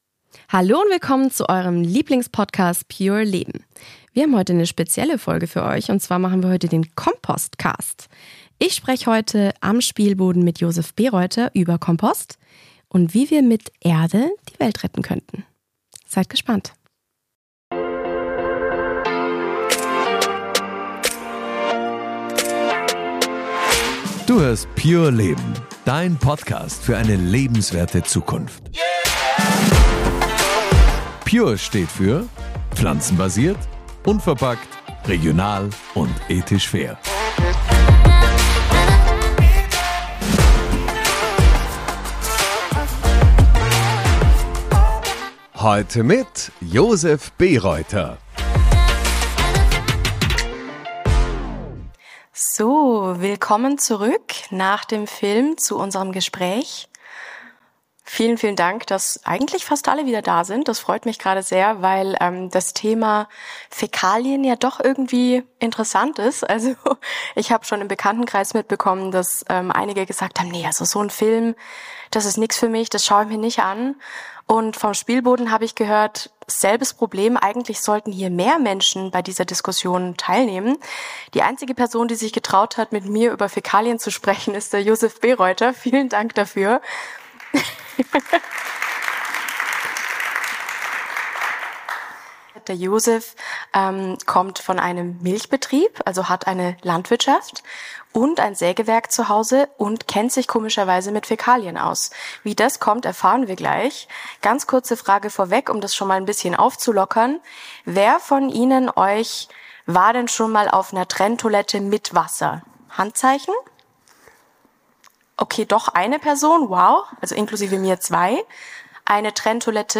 Beim Human Vision Filmfestival (am Spielboden Dornbirn)